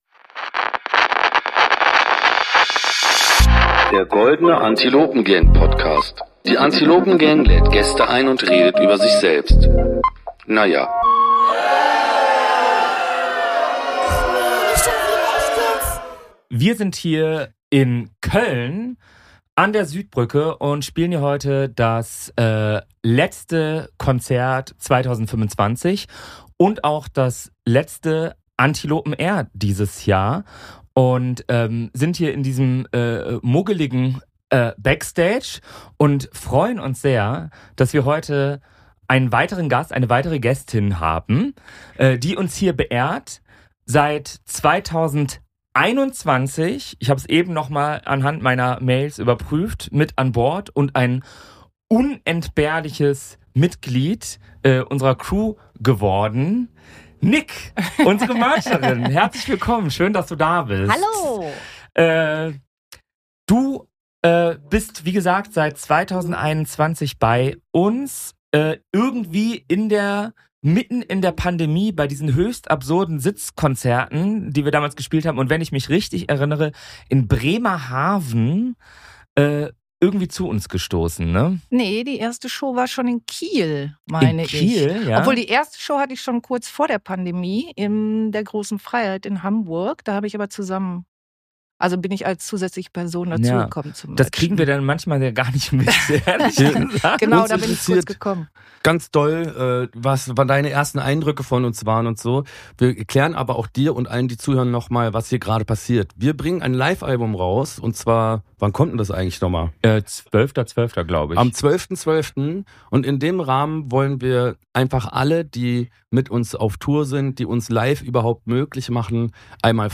Im Gespräch mit Danger Dan, Koljah und Panik Panzer erzählt sie, wie genau ihr Job funktioniert, charakterisiert das Antilopen Gang-Publikum hinsichtlich Kleidung und Sozialverhalten, und berichtet davon, wie sie von der Mutter eines Antilopen-Fans angegriffen wurde.